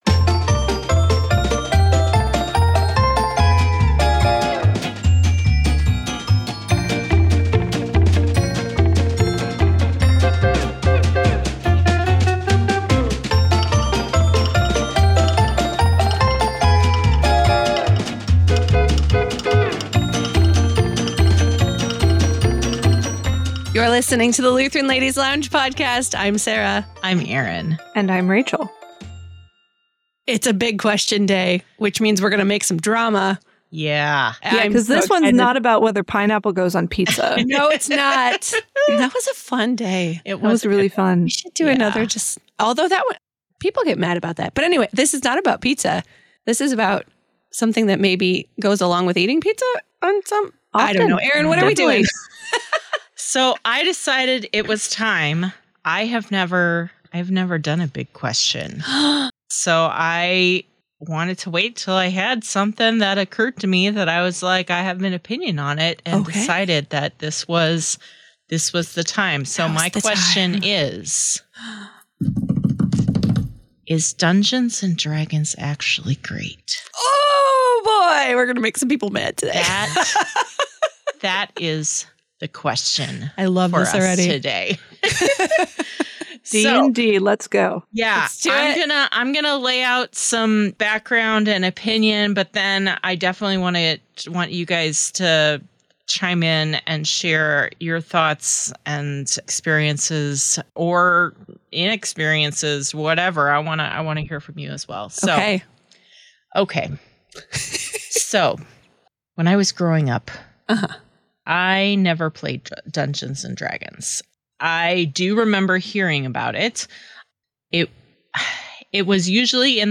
Have you scrolled through your podcasts, searching for one that catches your ear - a place you can escape to with inviting conversations, laughter, and fellowship with your Lutheran sisters?